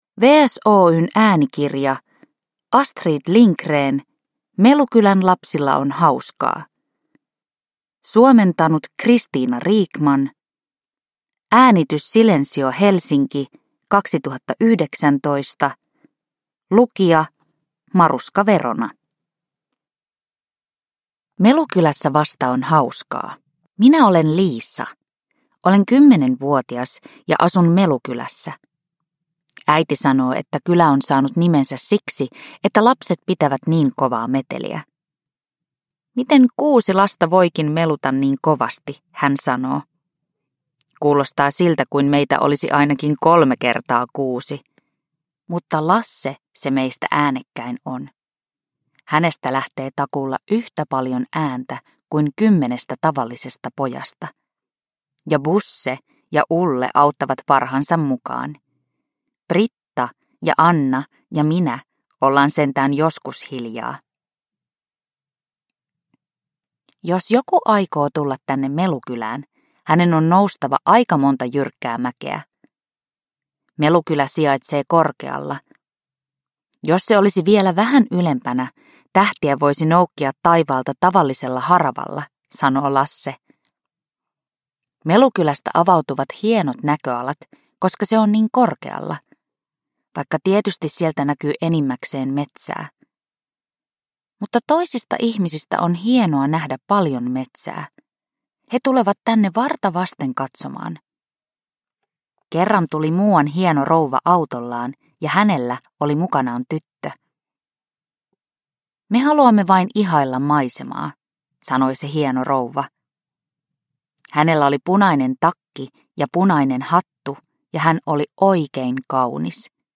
Melukylän lapsilla on hauskaa! – Ljudbok – Laddas ner